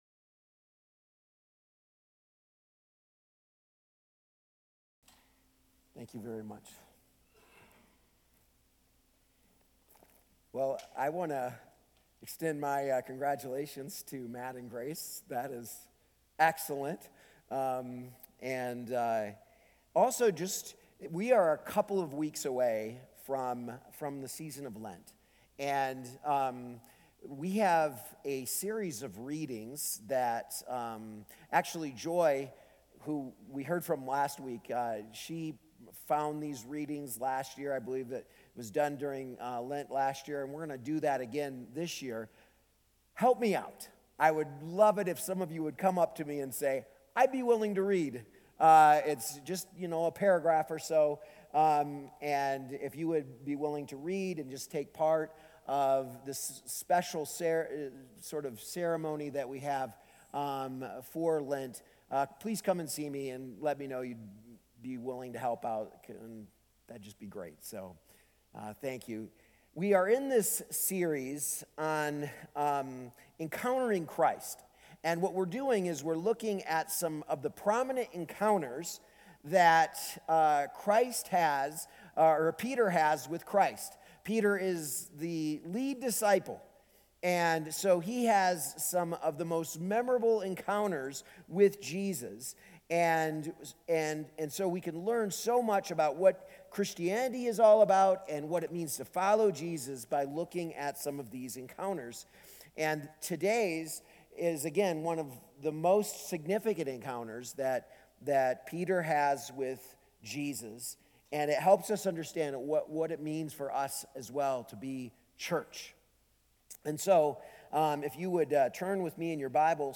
A message from the series "Encountering Christ."